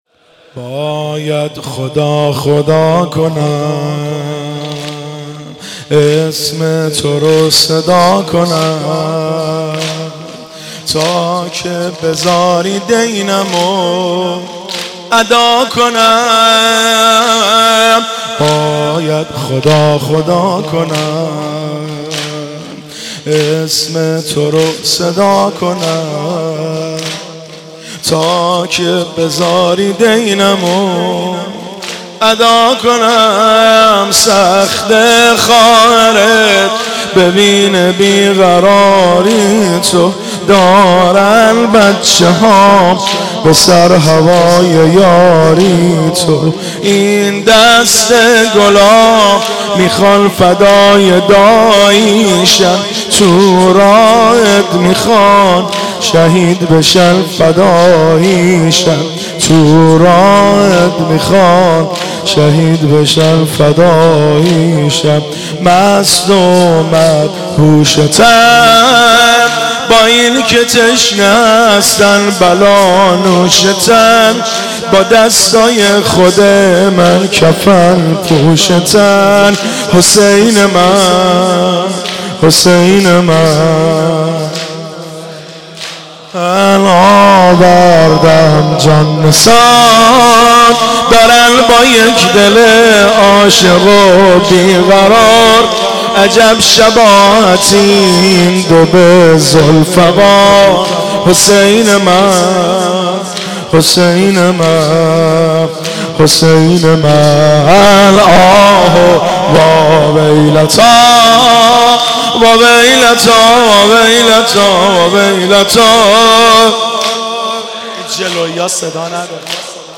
صوت مراسم شب چهارم محرم ۱۴۳۷ هیئت غریب مدینه امیرکلا ذیلاً می‌آید: